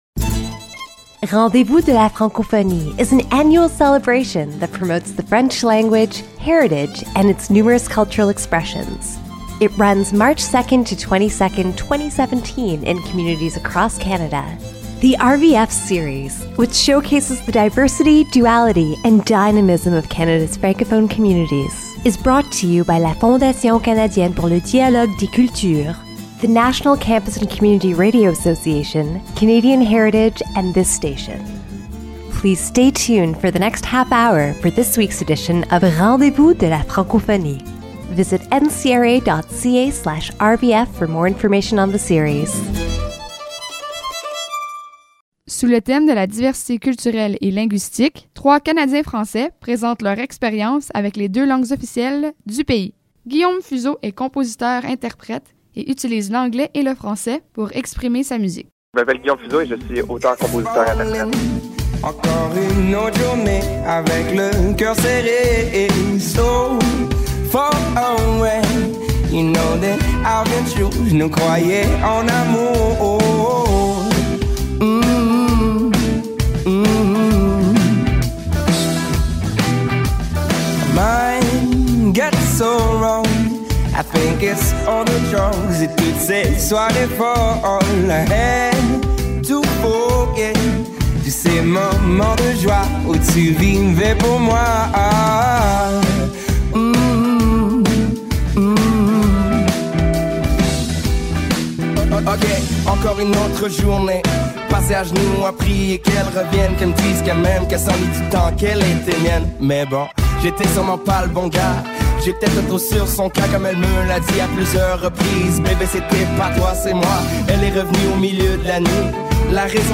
This audio segment consists in 3 interviews exploring how the French language influences 3 Artists